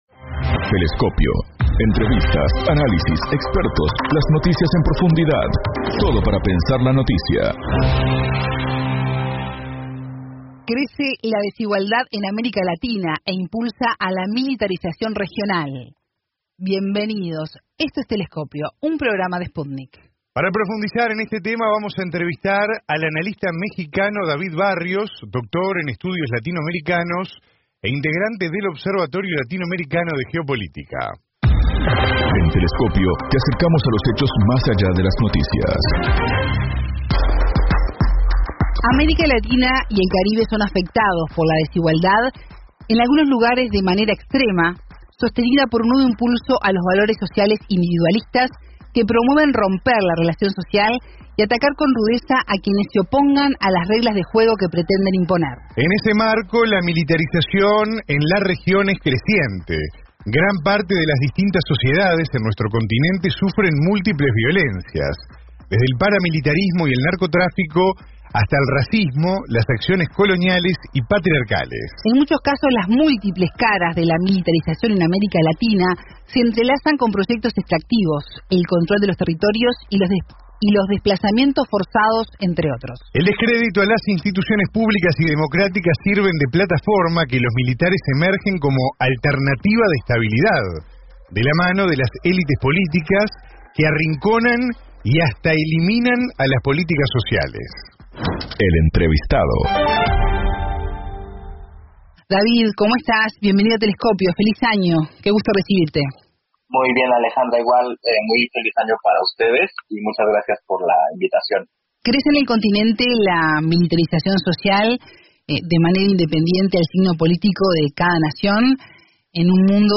Programa Radial Telescopio